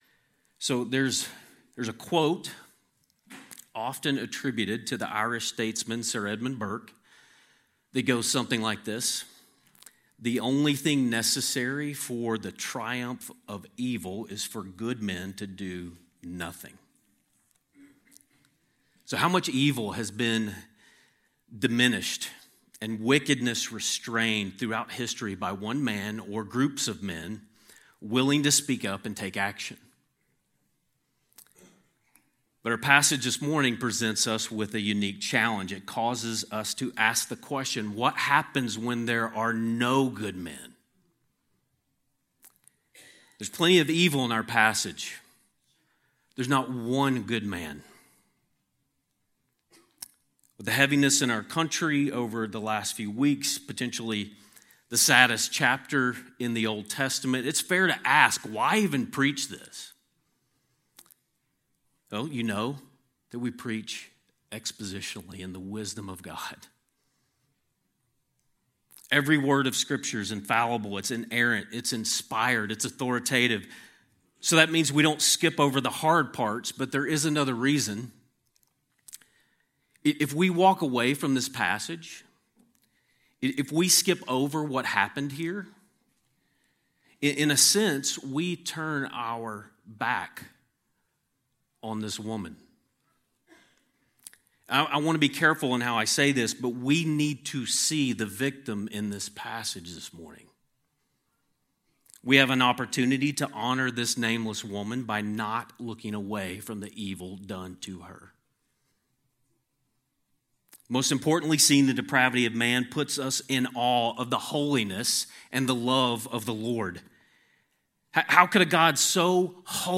A sermon on Judges 19:22-30